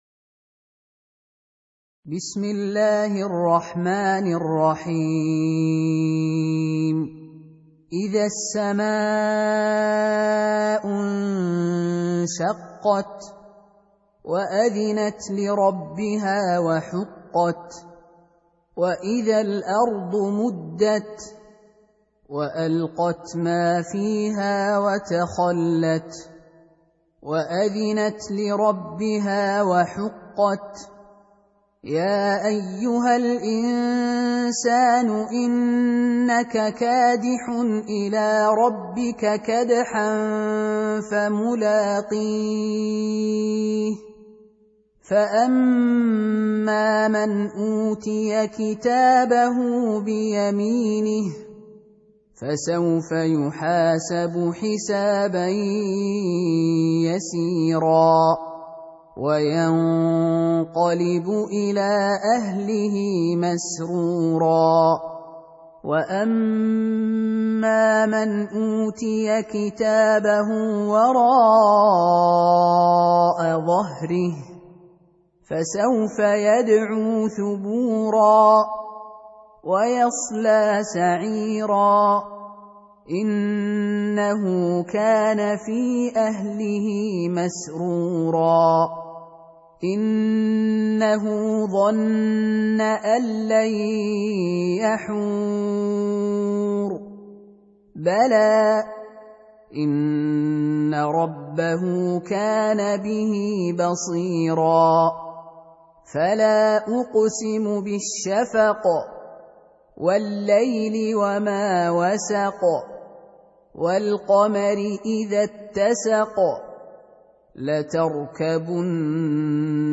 Surah Repeating تكرار السورة Download Surah حمّل السورة Reciting Murattalah Audio for 84. Surah Al-Inshiq�q سورة الإنشقاق N.B *Surah Includes Al-Basmalah Reciters Sequents تتابع التلاوات Reciters Repeats تكرار التلاوات